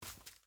Minecraft Version Minecraft Version 1.21.5 Latest Release | Latest Snapshot 1.21.5 / assets / minecraft / sounds / mob / turtle / baby / shamble4.ogg Compare With Compare With Latest Release | Latest Snapshot
shamble4.ogg